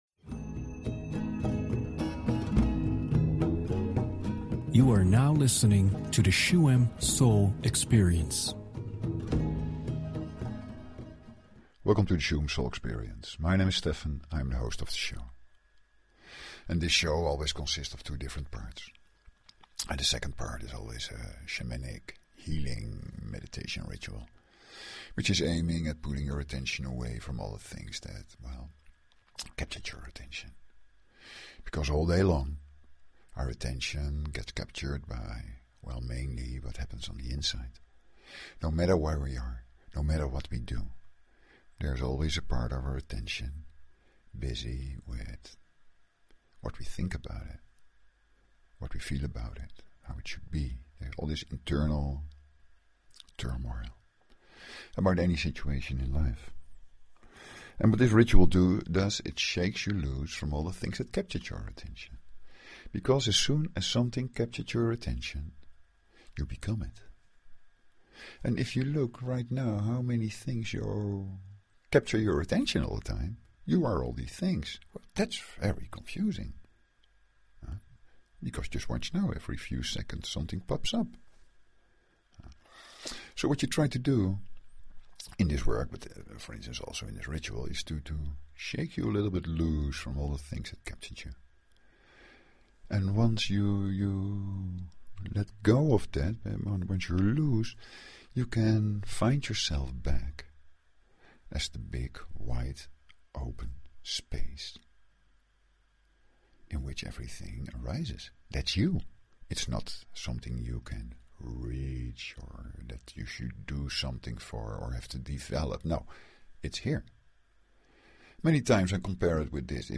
Talk Show Episode, Audio Podcast, Shuem_Soul_Experience and Courtesy of BBS Radio on , show guests , about , categorized as
The Reset Meditation in the second part of the show supports this process. During this meditation just let go by listening to the drum, rattle and chant and the ritual will take you into the silence and wideness of higher states of consciousness; the here and now.